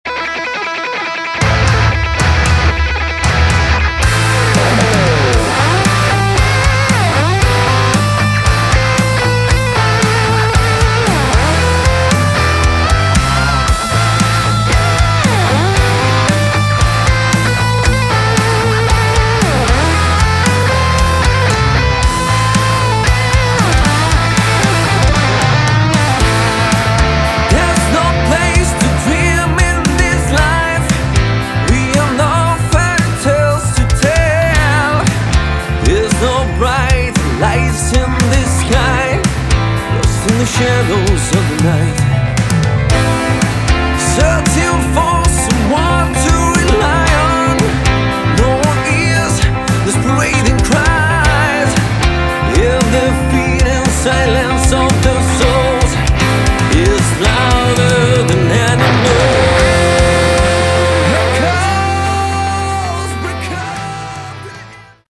Category: Hard Rock / AOR / Prog